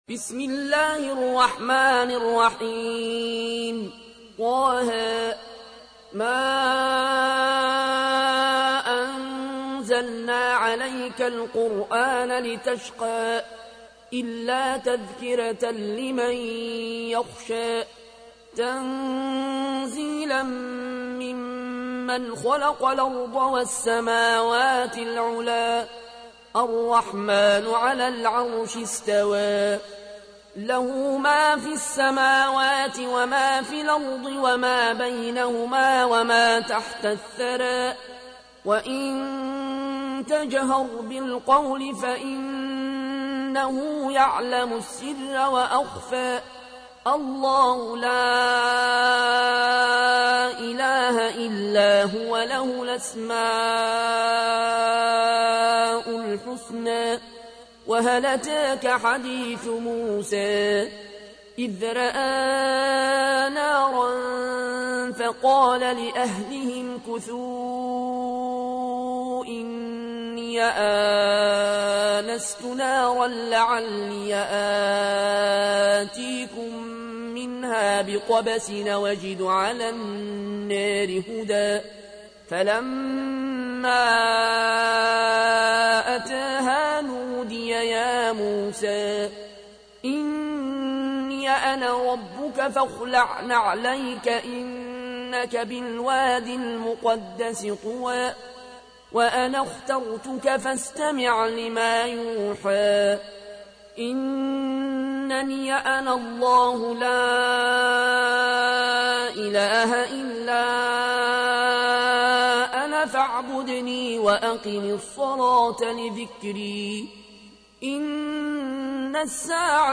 تحميل : 20. سورة طه / القارئ العيون الكوشي / القرآن الكريم / موقع يا حسين